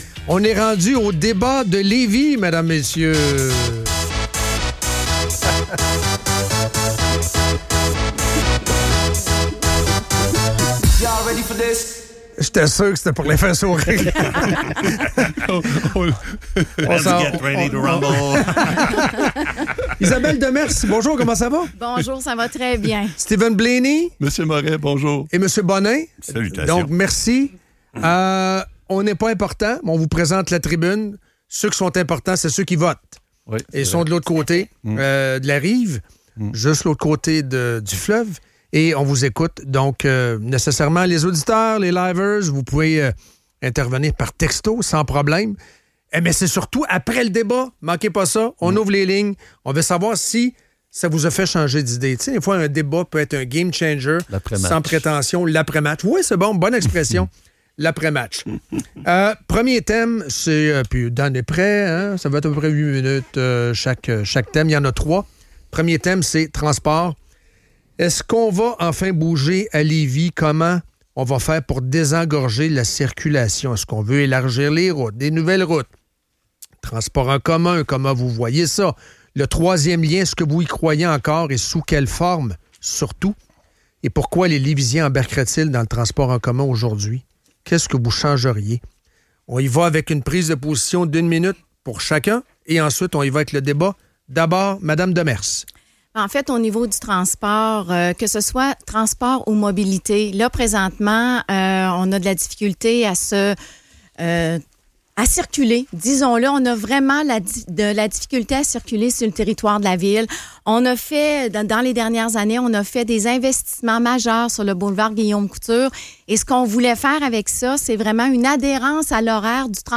DÉBAT LÉVIS: SRB, 3e Lien, développement, Logement, etc.